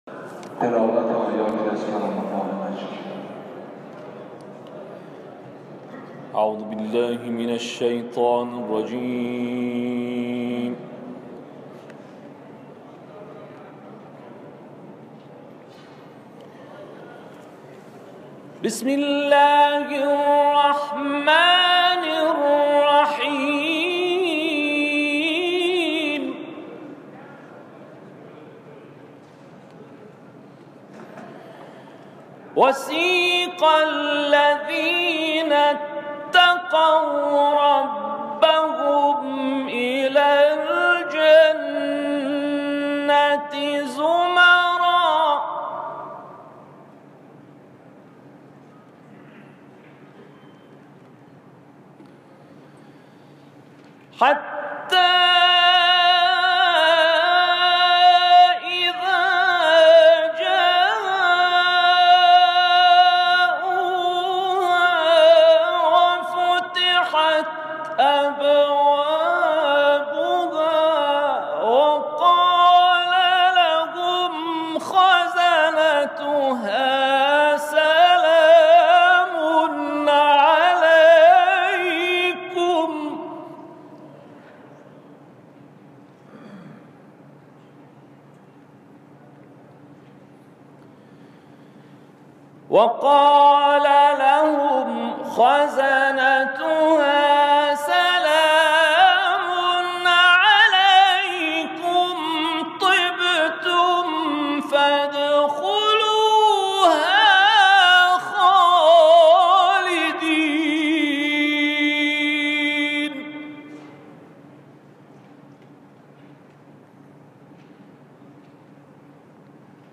تلاوت در مجلس